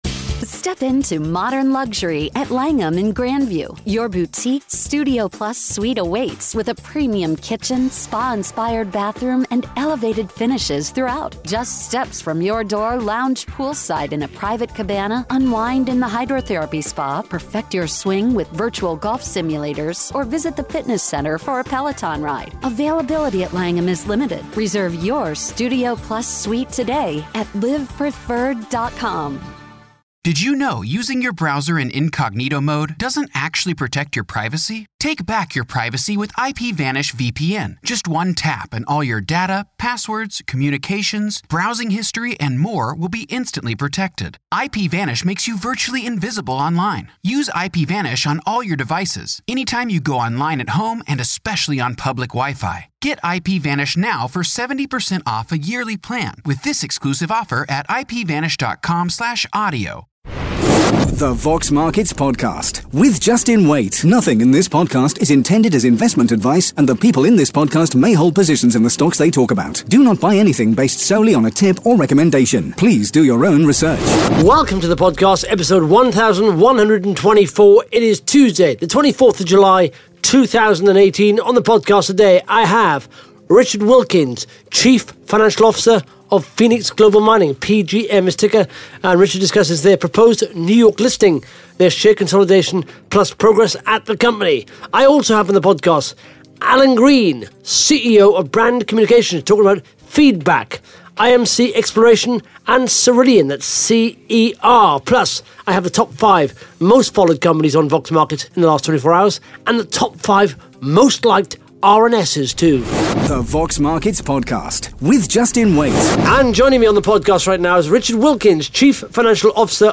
(Interview starts at 52 seconds)